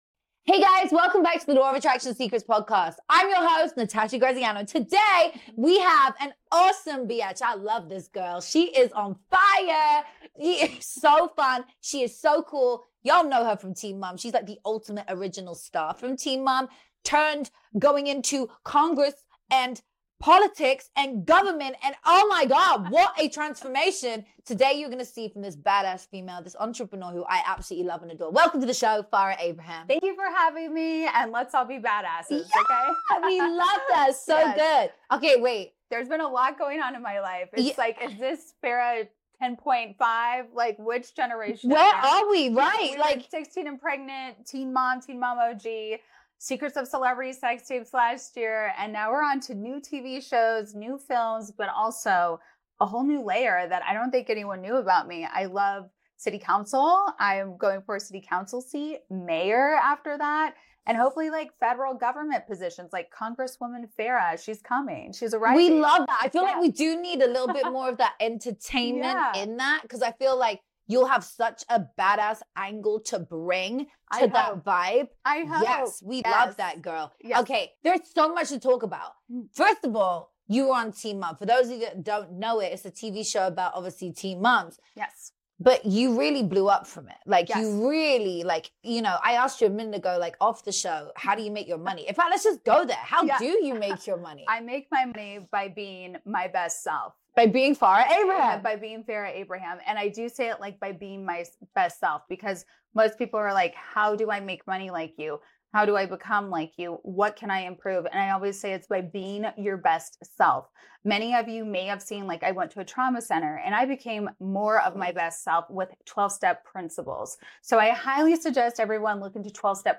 for an open and honest conversation about truth, healing, and reclaiming personal power. Farrah shares what it’s been like to live under public scrutiny, how.